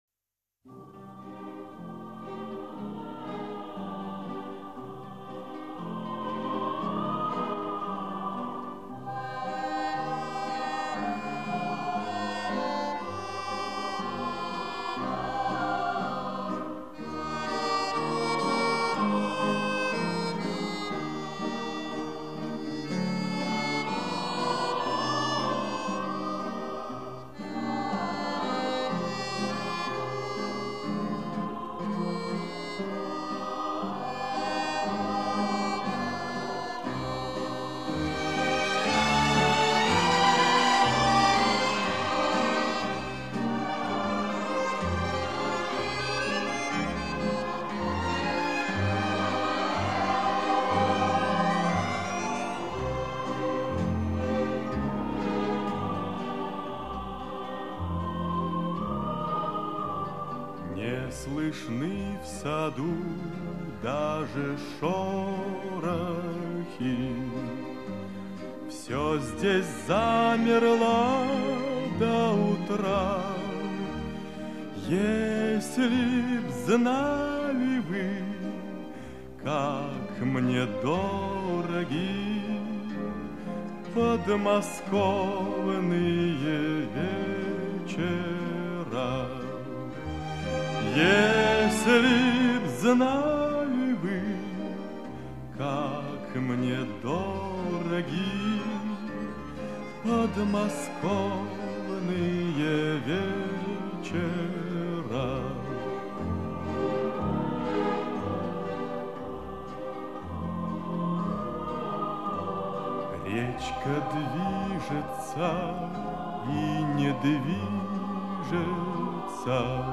Russian Folk, Russian Traditions